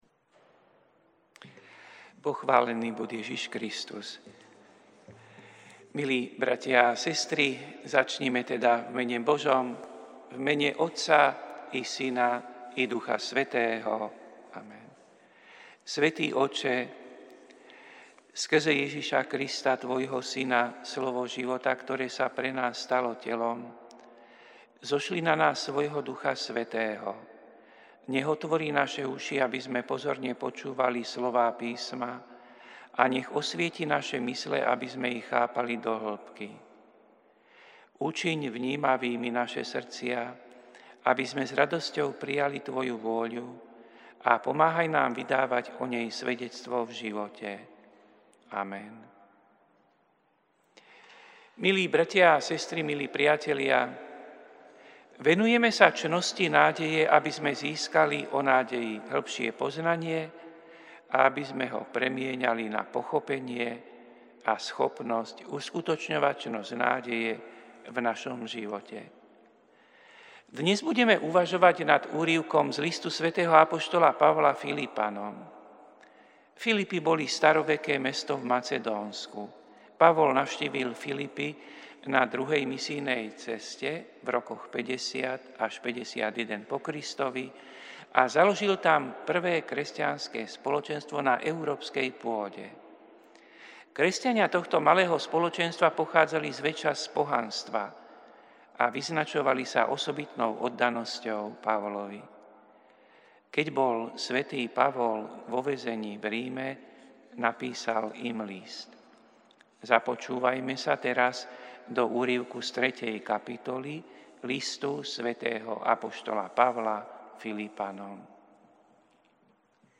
Prinášame plný text a audio záznam z Lectio divina, ktoré odznelo v Katedrále sv. Martina 2. apríla 2025.